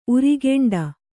♪ urigeṇḍa